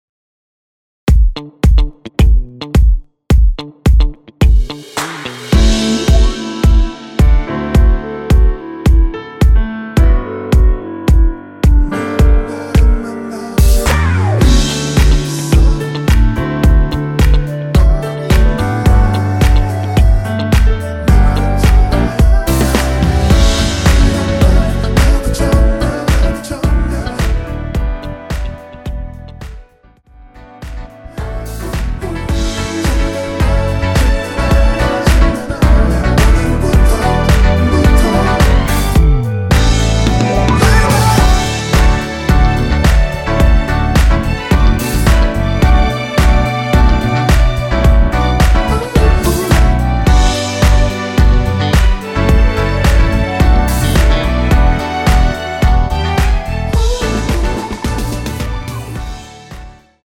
원키에서(-2)내린 멜로디와 코러스 포함된 MR입니다.(미리듣기 확인)
앞부분30초, 뒷부분30초씩 편집해서 올려 드리고 있습니다.
중간에 음이 끈어지고 다시 나오는 이유는